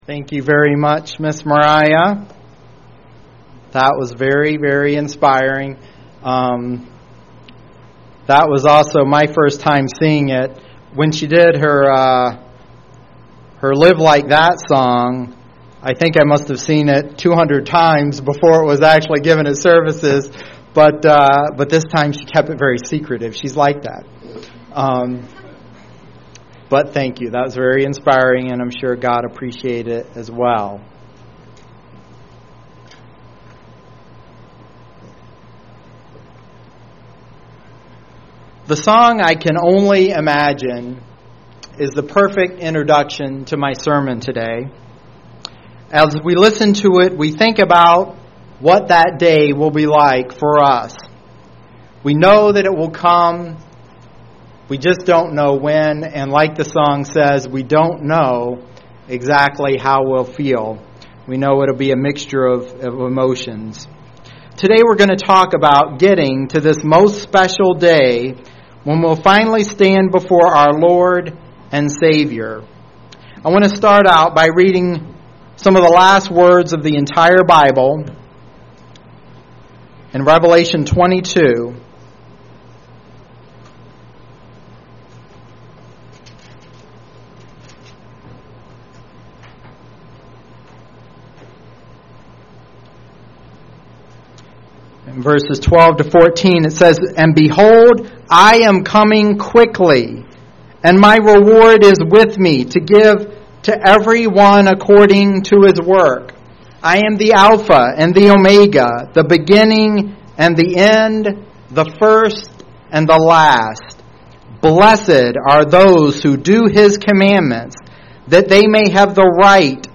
UCG Sermon Studying the bible?
Given in Lansing, MI